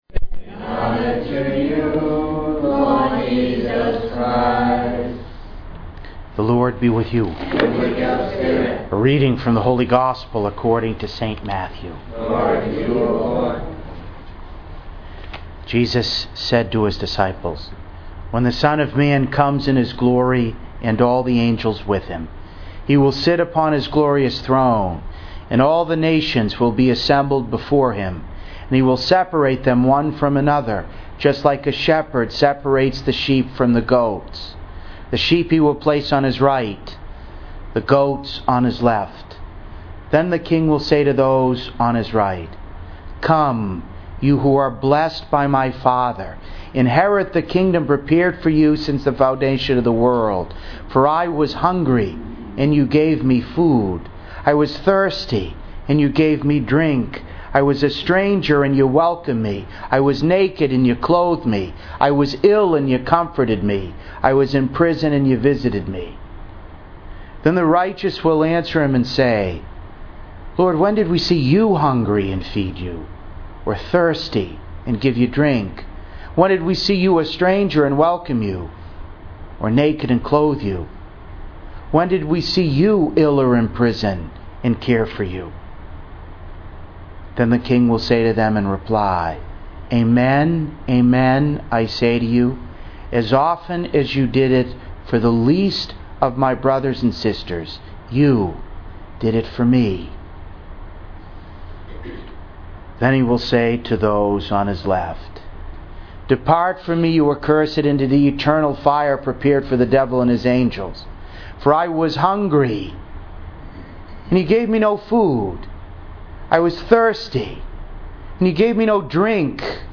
To listen to an audio recording of today’s homily, please click below: